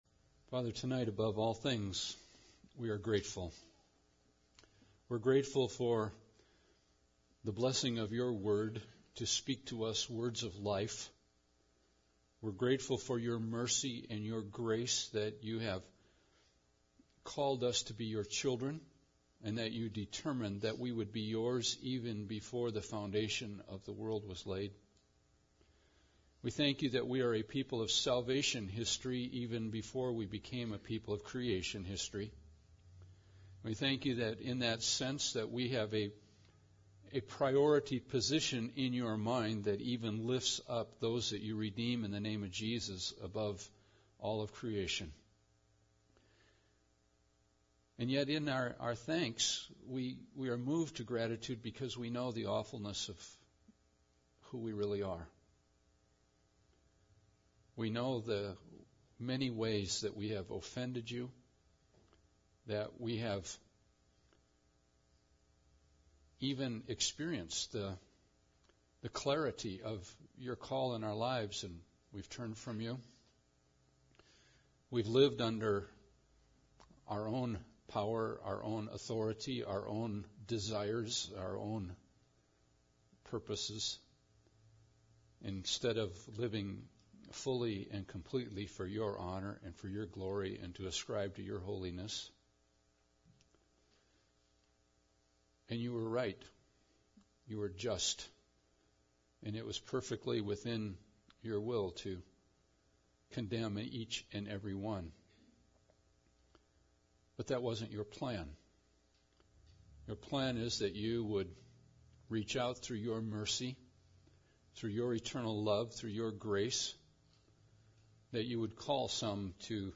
Believing The Father’s Plan – Good Friday Service